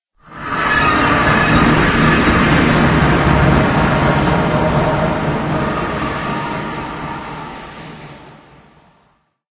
minecraft / sounds / portal / travel.ogg